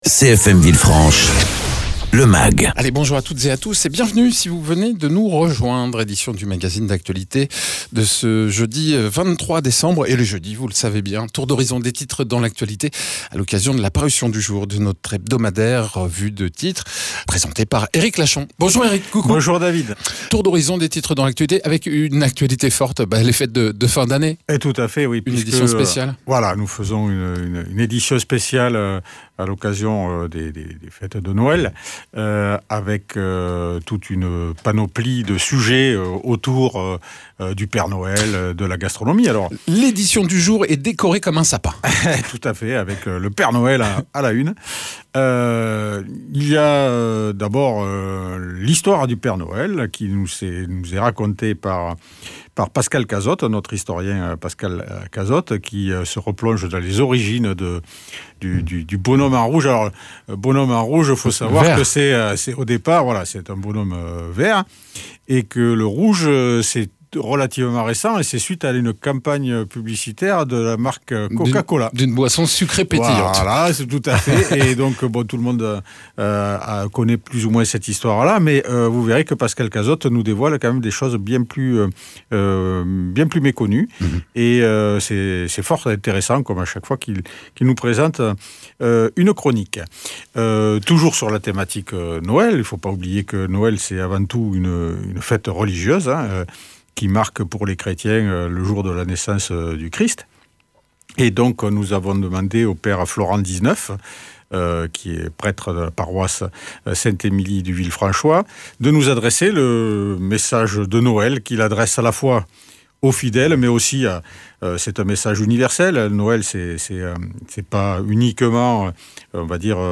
Reportage Boost’emploi du 3 décembre.
Mags